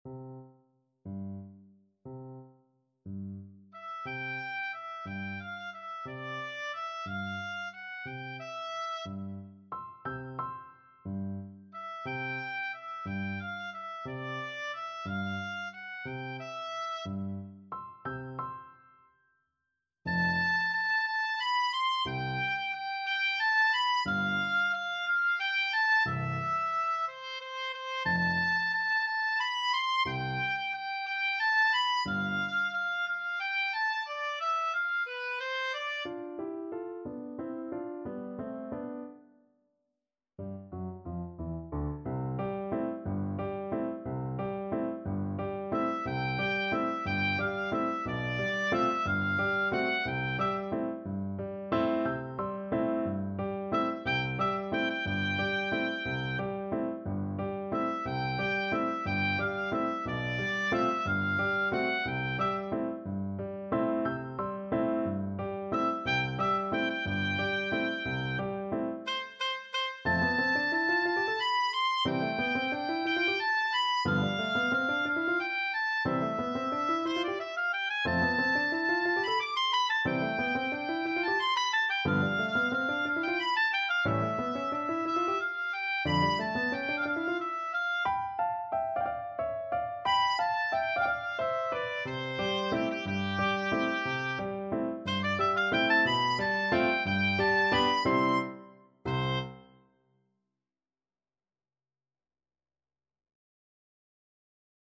Oboe
6/8 (View more 6/8 Music)
Pochissimo pi mosso = 144 . =60
C major (Sounding Pitch) (View more C major Music for Oboe )
Classical (View more Classical Oboe Music)